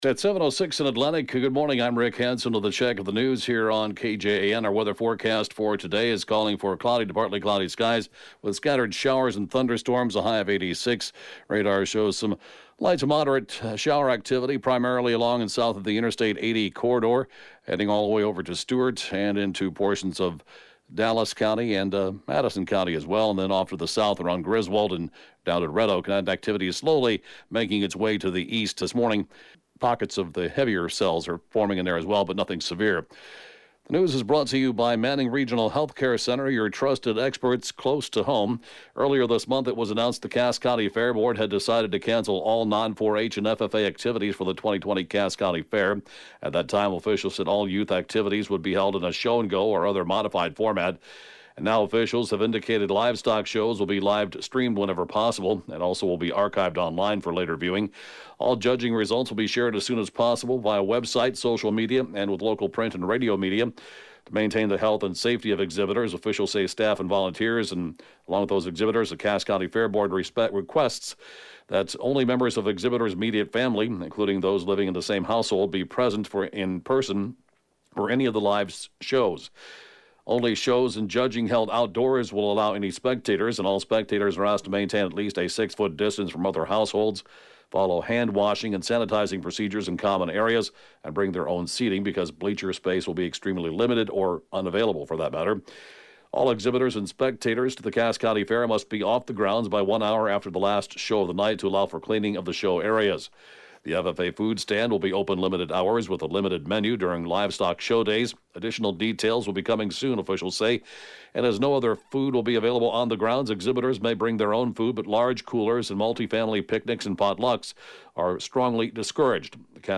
(Podcast) KJAN Morning News & Funeral report, 6/27/20